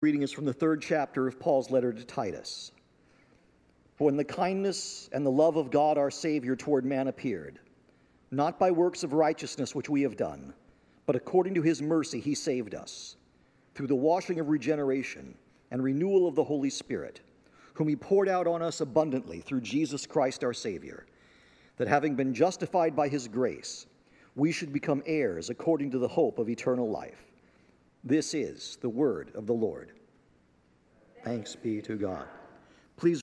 Dec 25, 2025 Xmas Epistle Reading – Concordia Lutheran Church Findlay